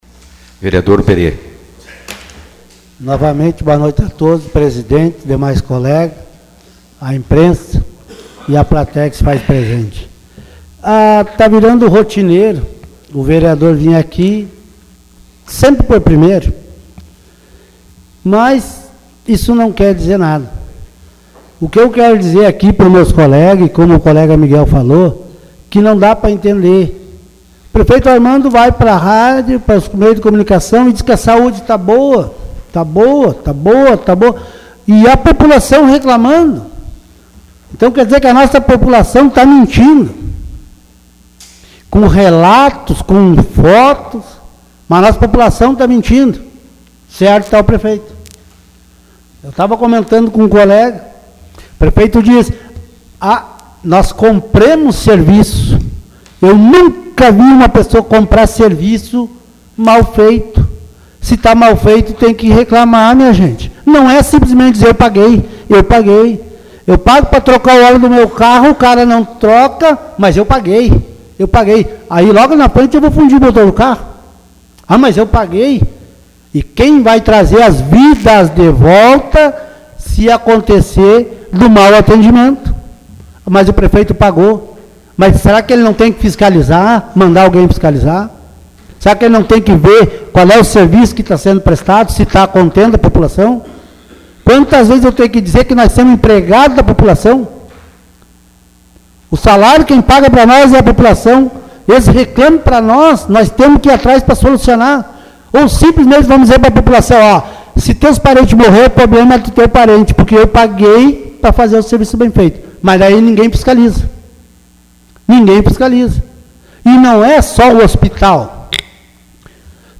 Os vereadores de Sobradinho estiveram reunidos nesta segunda-feira (25), durante a 7ª sessão ordinária. Foi aprovado pedido de vista, feito pelo vereador Miguel Vieira, ao Projeto de Lei que dispõe sobre o Sistema de Controle Interno do Município.